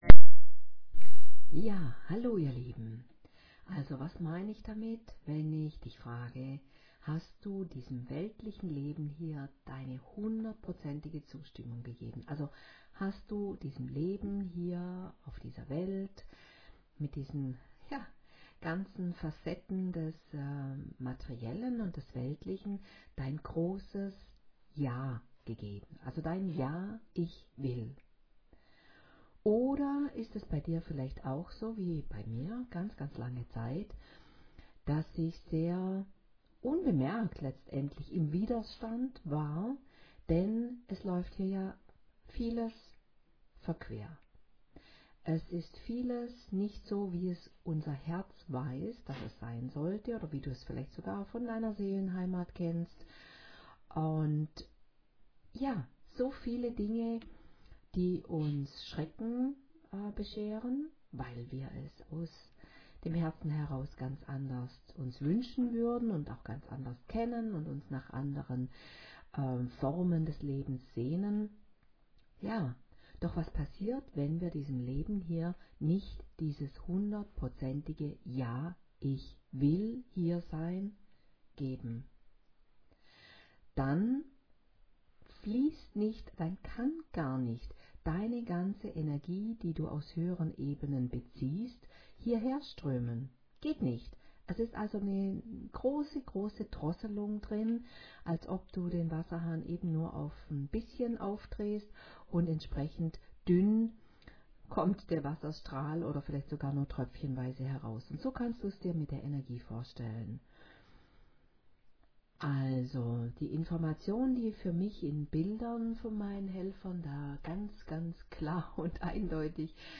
Mit dieser Sprachnachricht soll ich dir übermitteln (so die Anweisung >von oben<) wie du dich voll und ganz in deinen Energie-Lebensstrom einbinden kannst.